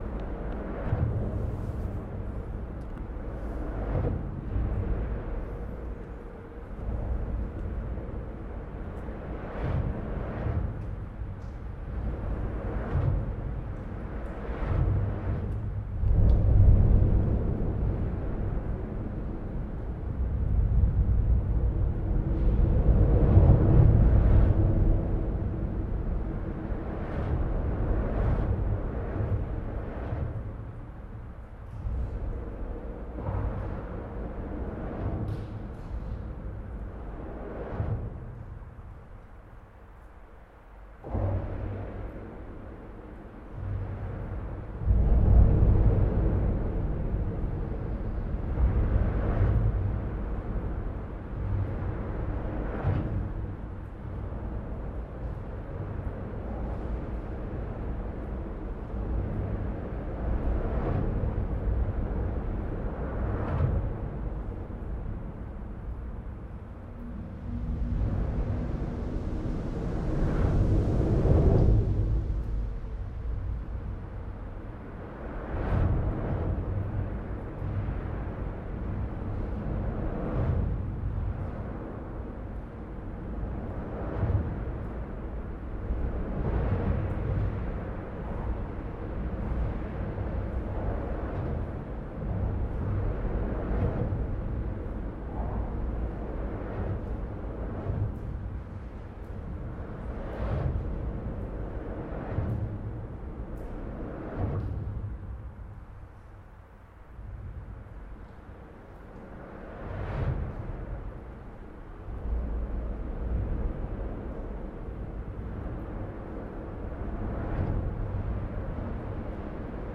Rhythmic bridge, Oxford
The sound of a road bridge that makes an unusually rhythmic sound when cars pass overhead.